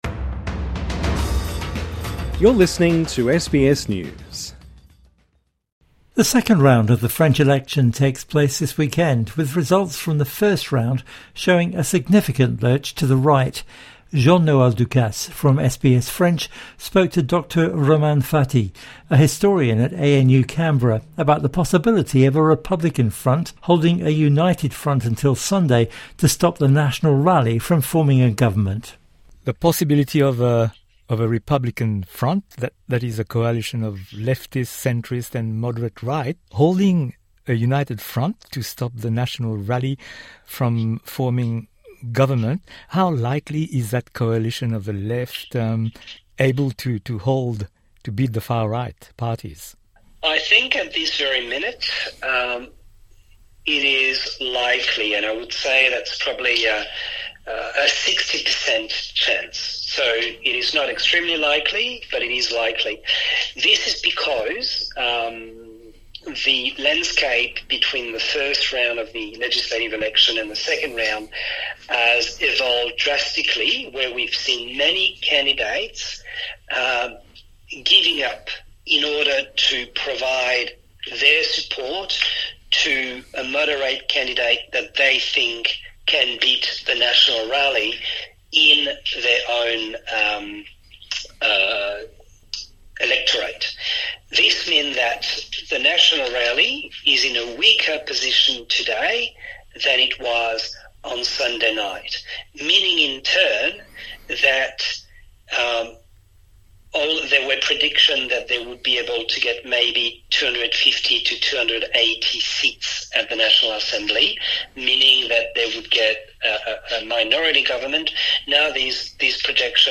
INTERVIEW: Preview of French Election second round